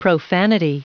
Prononciation du mot profanity en anglais (fichier audio)
Prononciation du mot : profanity